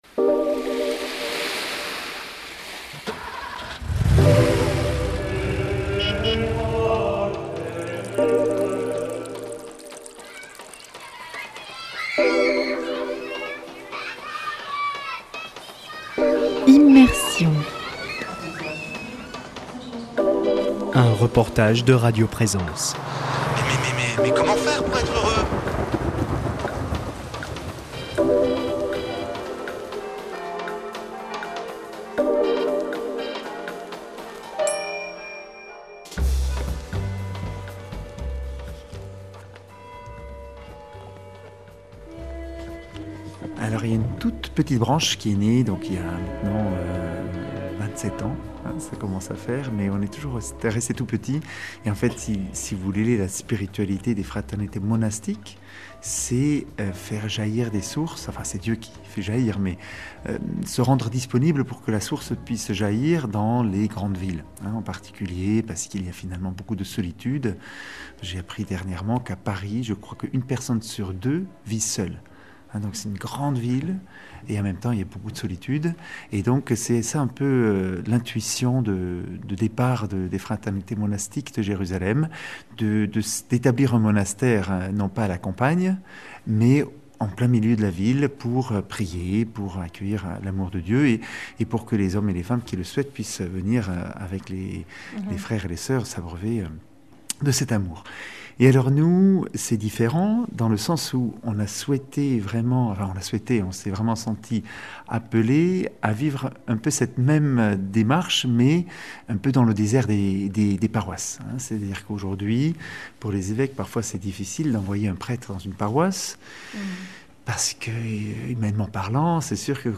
[ Rediffusion ] A Tarbes au service du diocèse, insérées au cœur du monde pour participer à la mission pastorale de l’Eglise diocésaine, deux fraternités apostoliques de Jérusalem, une communauté de sœurs et une communauté de frères, dont la vie s’alimente à la sève monastique, ont également la charge et la responsabilité de l’ensemble paroissial de la cathédrale.